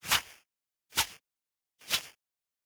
Soundeffects